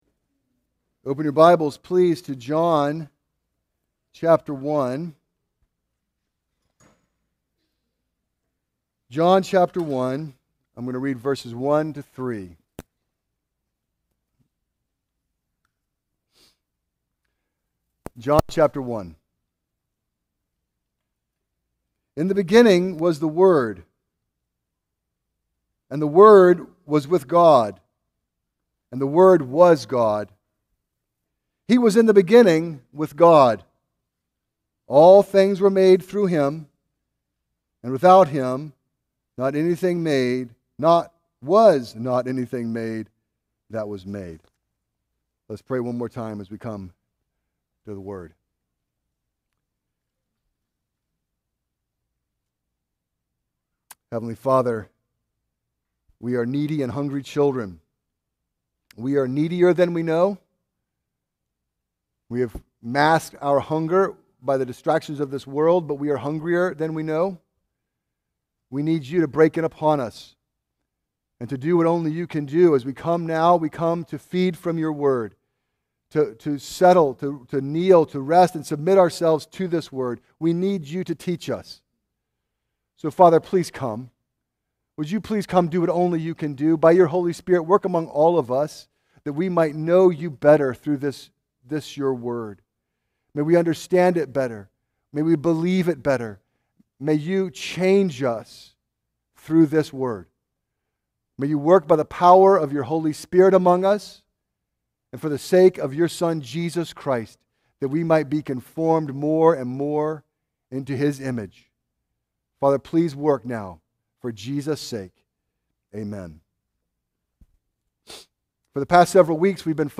A message from the series "Exposition of John."